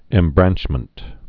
(ĕm-brănchmənt)